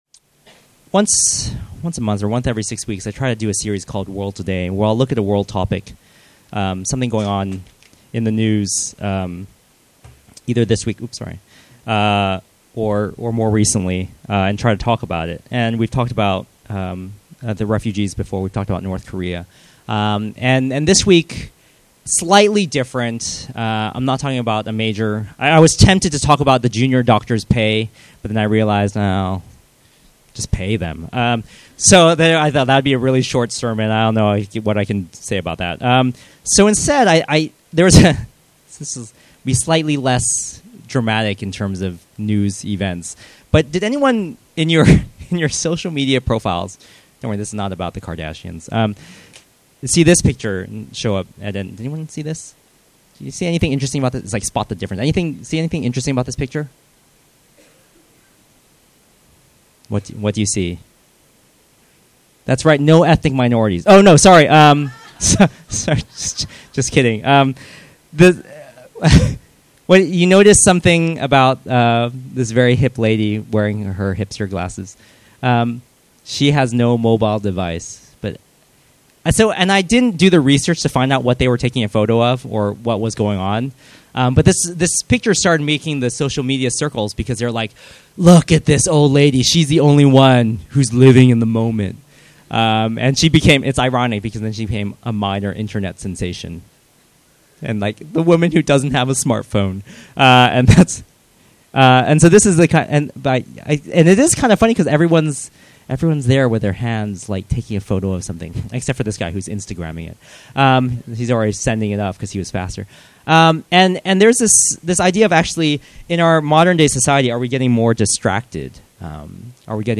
Category Sermon Audio